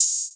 OpHat (Short-4).wav